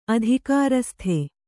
♪ adhikārasthe